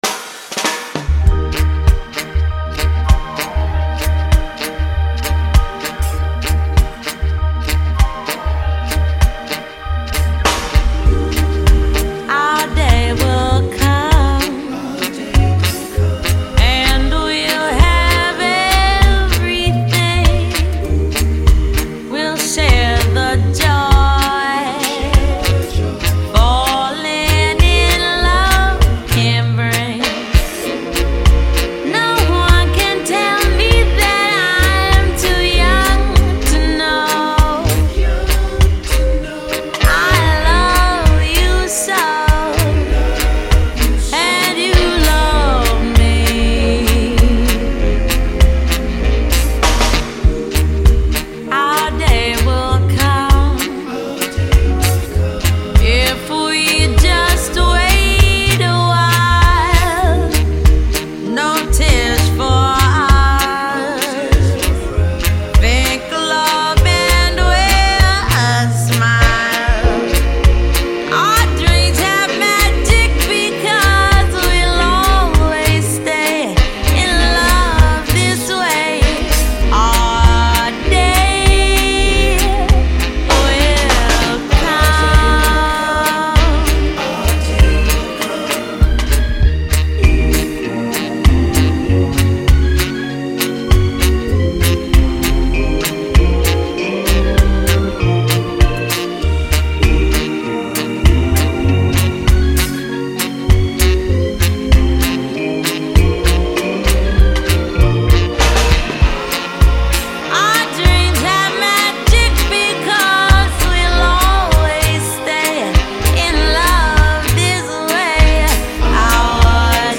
cool cat rendition